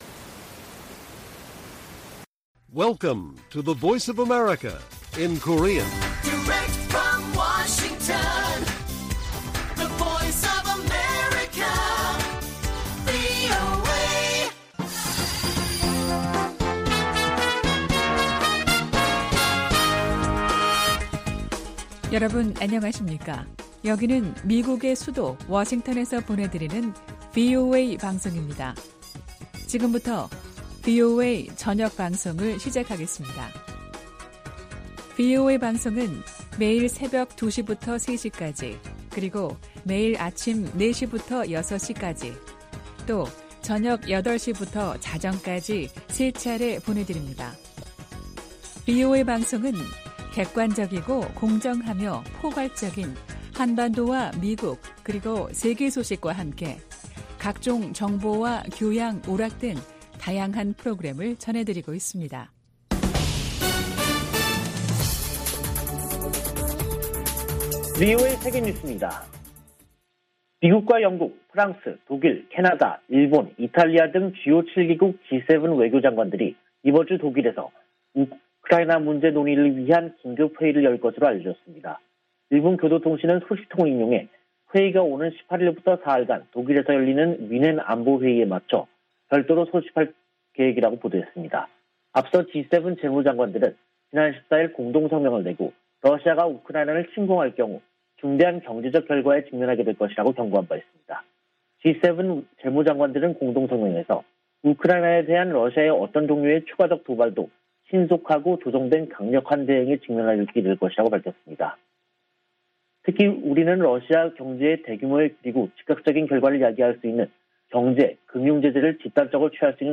VOA 한국어 간판 뉴스 프로그램 '뉴스 투데이', 2022년 2월 16일 1부 방송입니다. 웬디 셔먼 미 국무부 부장관이 일본 외무성 사무차관과 전화 협의를 갖고 북한에 진지한 외교 복귀를 촉구했습니다. 한국 대선 이후 새 대통령 취임전까지 미국과 일본은 새 한국 안보팀과 관계를 발전시키는 것이 중요하다고 미 국무부 부차관보가 밝혔습니다. 유엔인구기금의 대북 인도지원 계획이 3차례 제재 면제 기간 연장에도 북한의 국경 봉쇄 조치로 끝내 무산됐습니다.